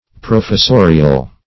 professorial - definition of professorial - synonyms, pronunciation, spelling from Free Dictionary
Professorial \Pro`fes*so"ri*al\, a. [L. professorius: cf. F.